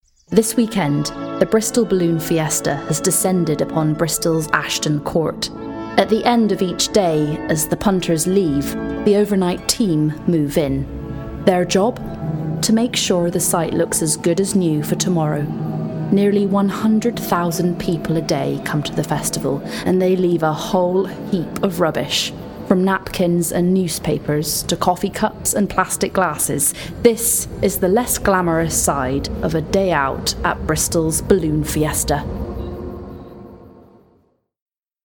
• Native Accent: Bristol, RP
• Home Studio